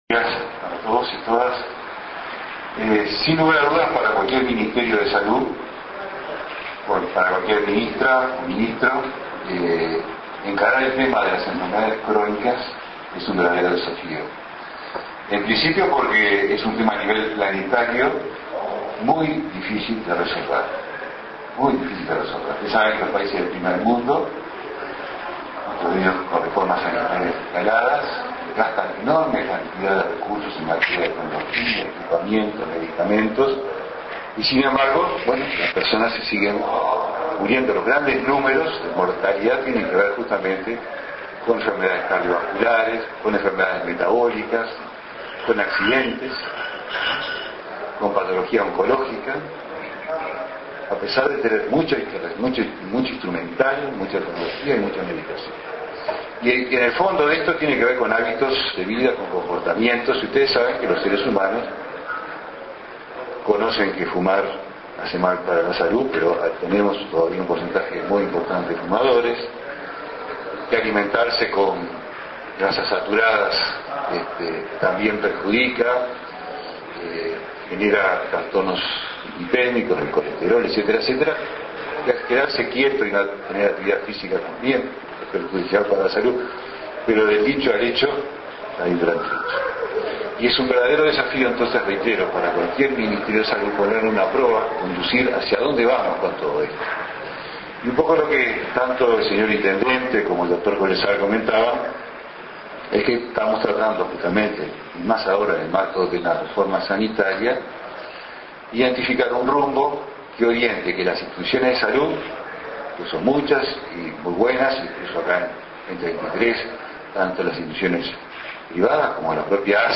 Palabras del Director General de la Salud, Jorge Basso, en el lanzamiento del programa Previniendo.